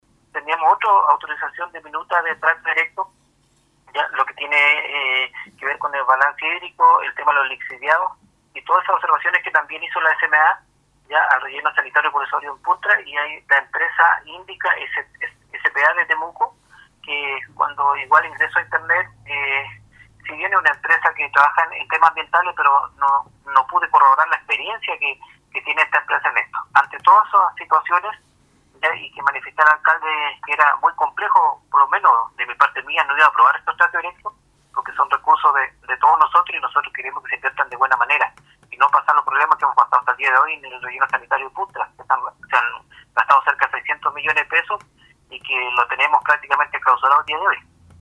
Al respecto conversamos con el concejal Alex Muñoz, quien señaló que no se pudo comprobar la experiencia de estas empresas en ejecuciones en rellenos sanitarios.